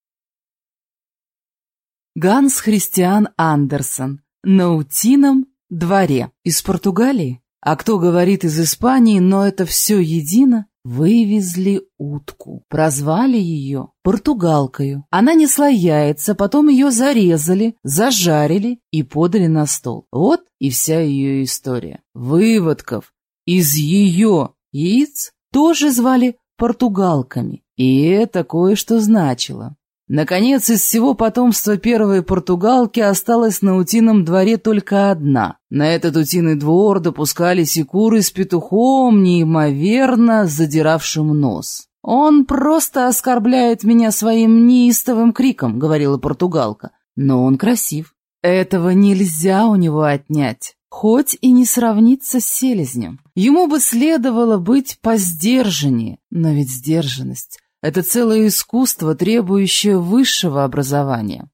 Аудиокнига На утином дворе | Библиотека аудиокниг